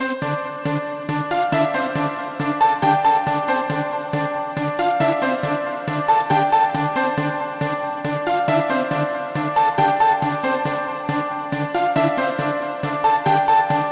令人振奋的恍惚合成物...│ 单曲
描述：恍惚的样本，振奋人心的恍惚，渐进的恍惚，经典的恍惚，恍惚的合成器，恍惚的垫子，恍惚的旋律，恍惚的和弦，恍惚的进展，恍惚的声音样本。
Tag: trance 振奋人心的trance progressive_trance 经典trance trance_synthesizer trance_pad trance_melody trance_chords trance_progression